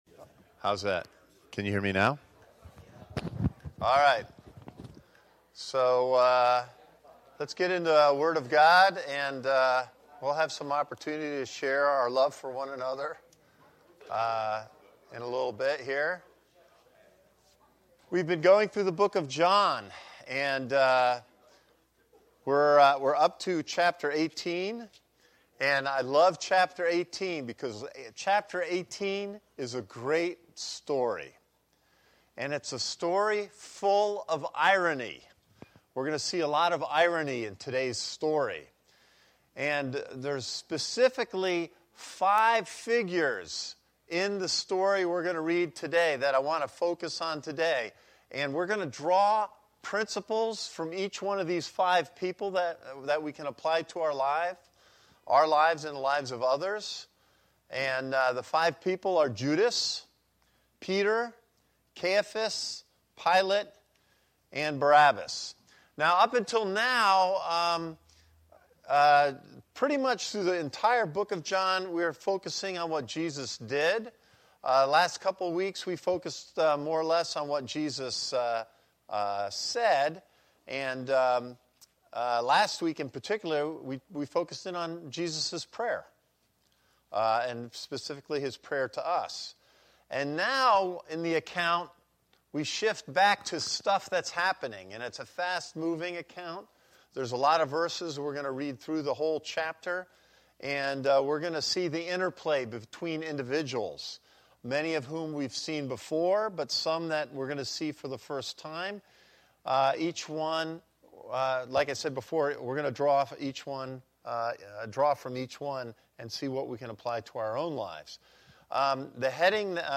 A message from the series "Sunday Service."
This is the main Sunday Service for Christ Connection Church